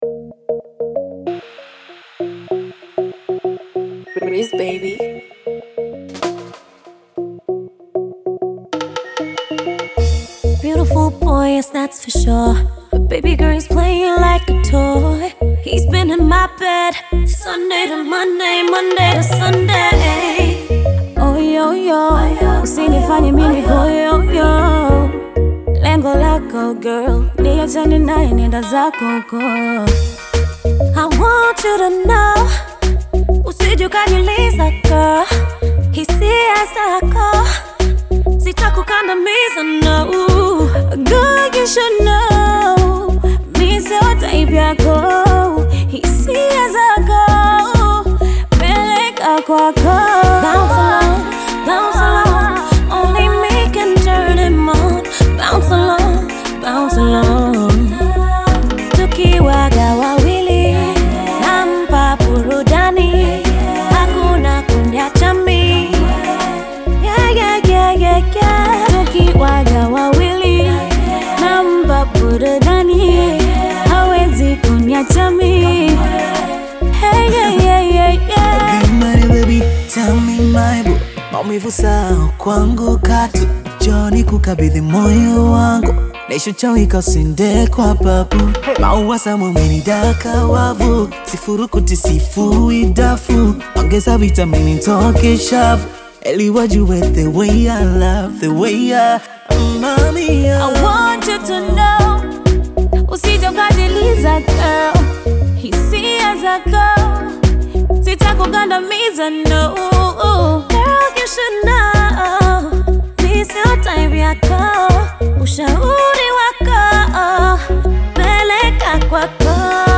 two of the baddest vocalists out of Tanzania